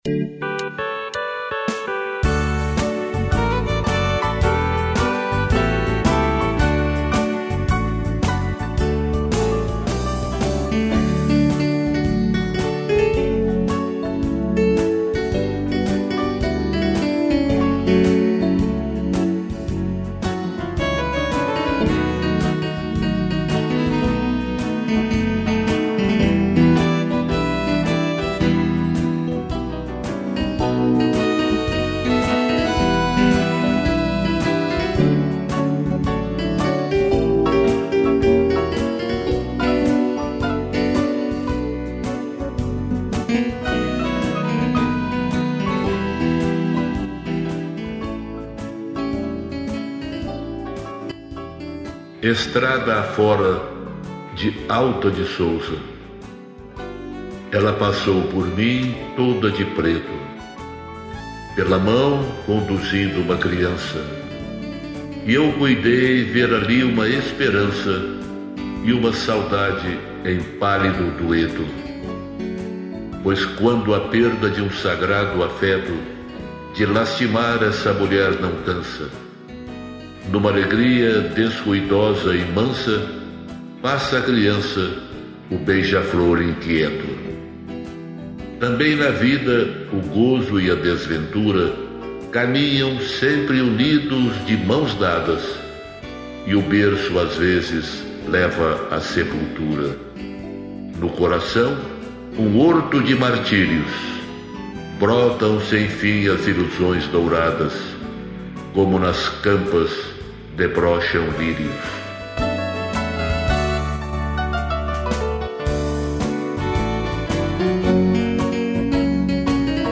piano, violino e violão